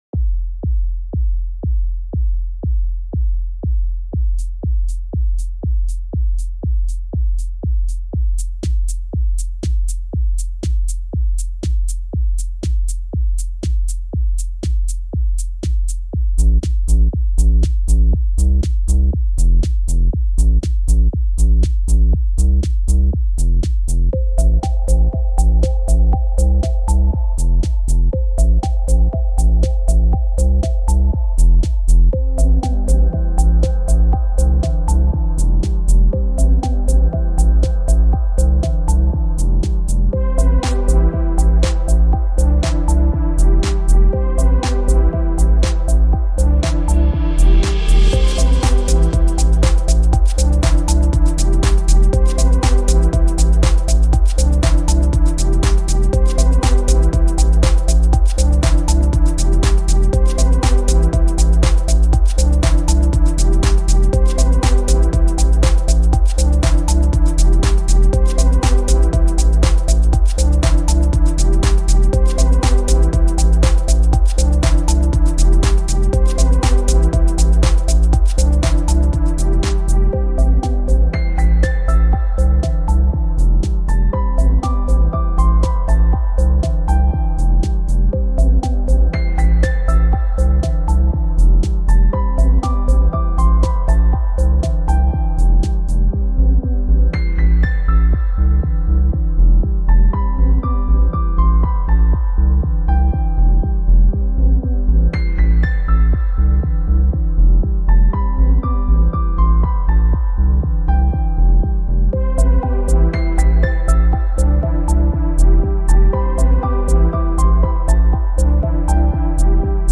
genre:deep house